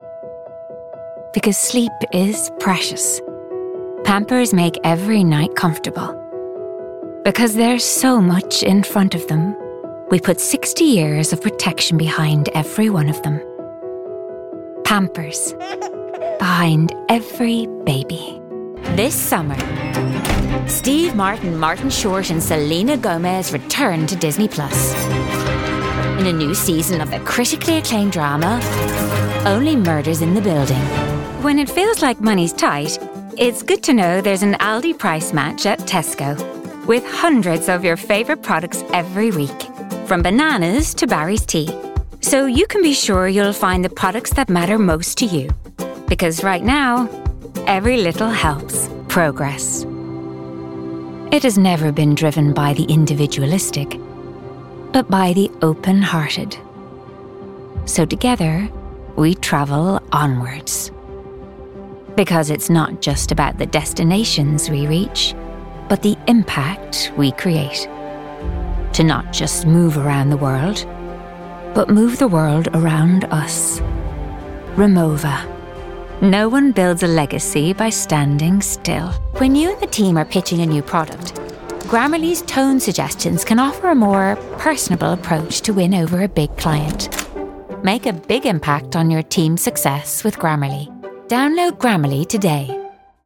Female
Remote set up including Aston Origin condenser mic.
20s/30s, 30s/40s
Irish Dublin Neutral, Irish Neutral